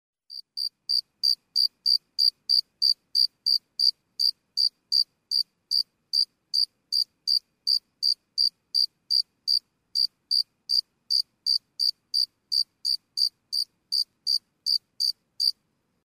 Звуки сверчков
Стрекот сверчка